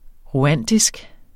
rwandisk adjektiv Bøjning -, -e Udtale [ ʁuˈanˀdisg ] Betydninger fra Rwanda; vedr. Rwanda eller rwanderne